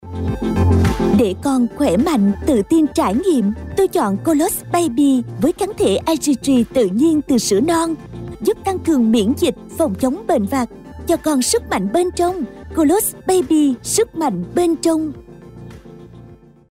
女越南1T-14 越南语女声 低沉|激情激昂|大气浑厚磁性|娓娓道来|科技感|积极向上|时尚活力|神秘性感|素人